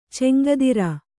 ♪ cemgadira